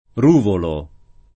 Ruvolo [ r 2 volo ]